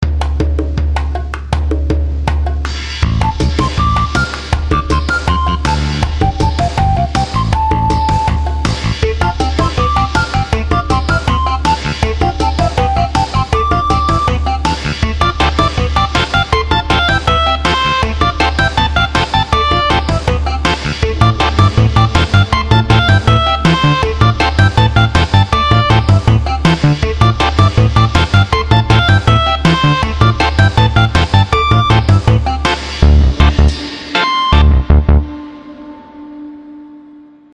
non-loop. folk song